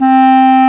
Clarinet_C4_22k.wav